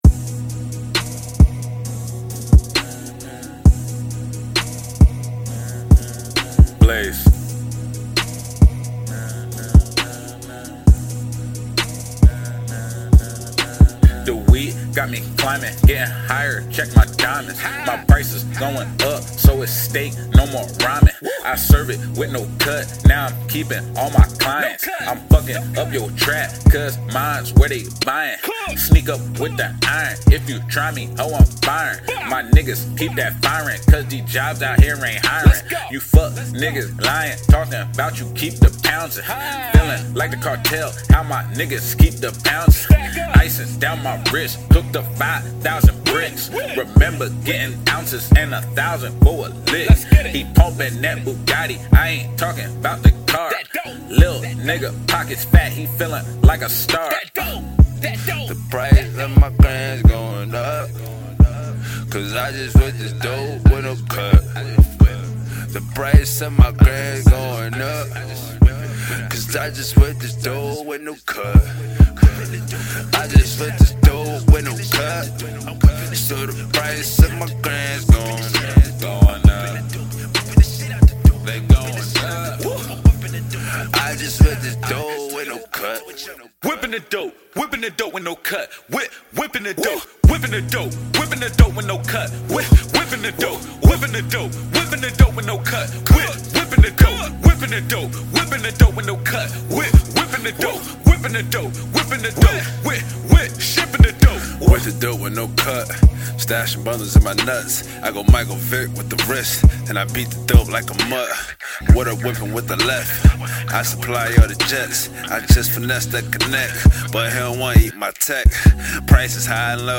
Hiphop
Description: New Trap Ish